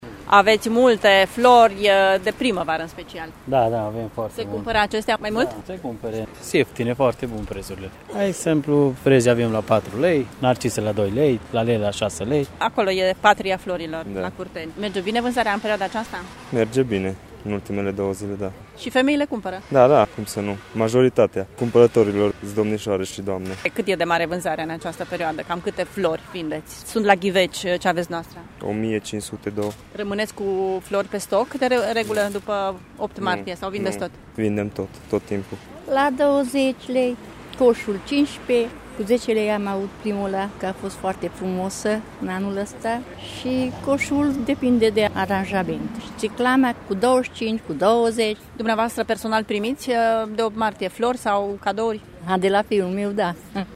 Totuși, cei mai mulți clienți din ultimele zile sunt… doamnele, spun vânzătorii: